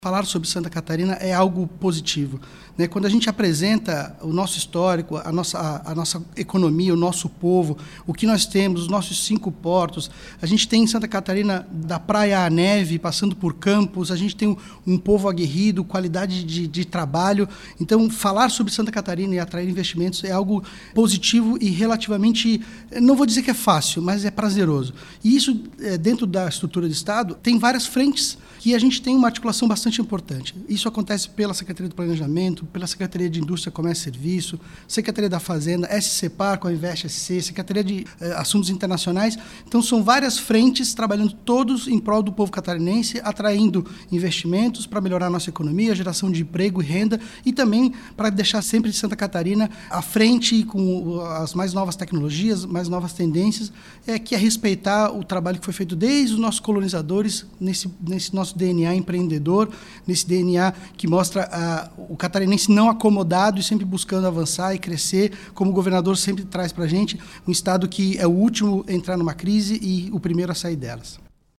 O secretário de Estado do Planejamento de Santa Catarina, Edgard Usuy, salienta o resultado positivo das ações estratégicas do governo em parceria com o setor produtivo catarinense e os outros órgãos do estado que reflete no crescimento expressivo dos empregos para todos os catarinenses:
SECOM-Sonora-secretario-do-Planejamento.mp3